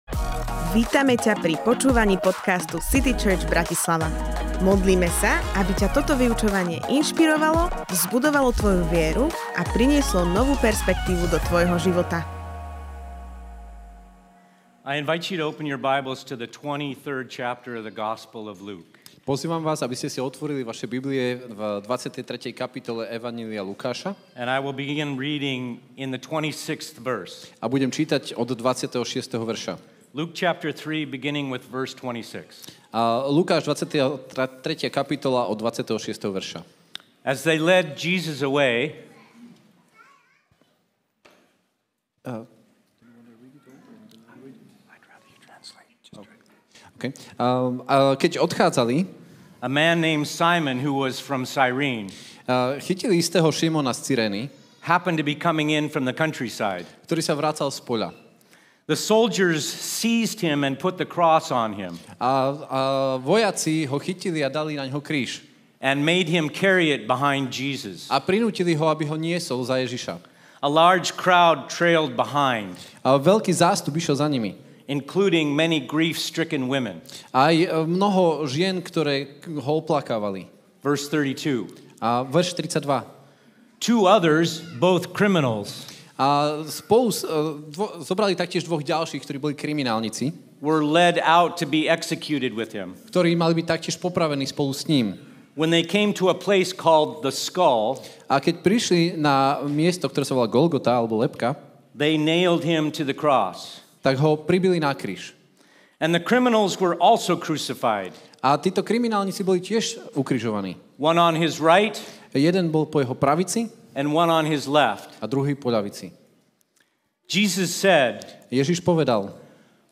Veľkonočné bohoslužby v CityChurch